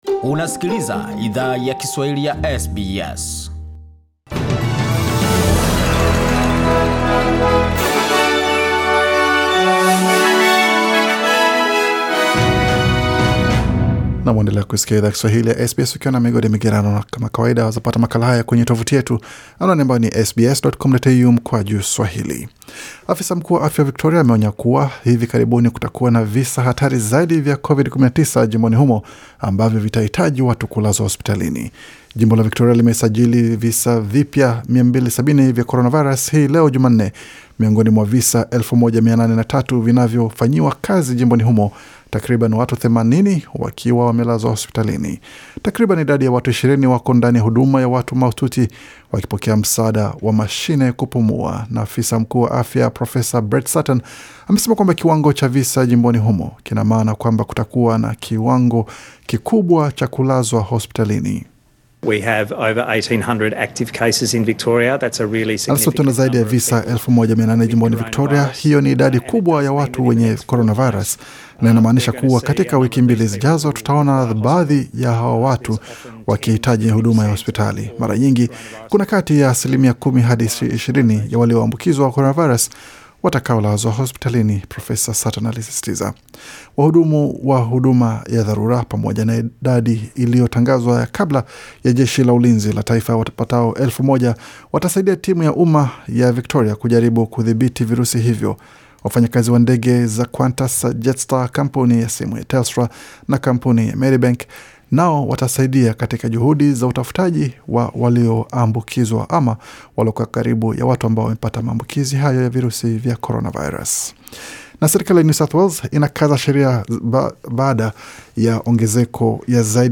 Taarifa ya habari 14 Julai 2020